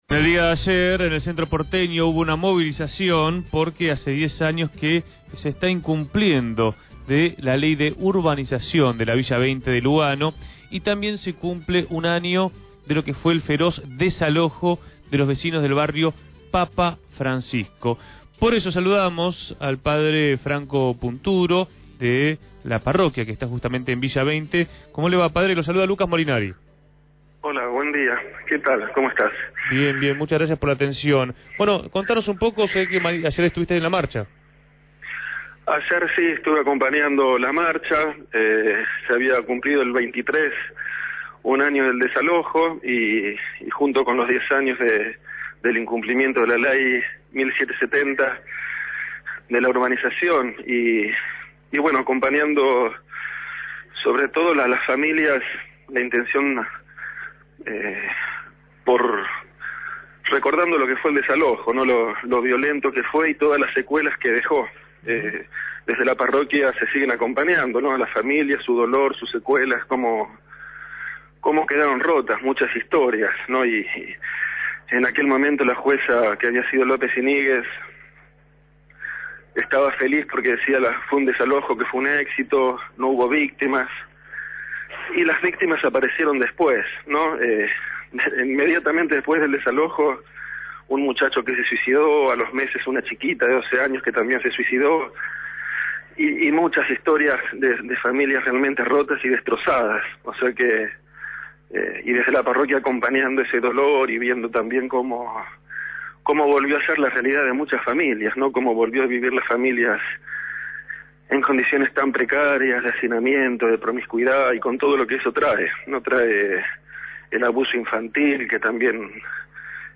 fue entrevistado en Punto de Partida.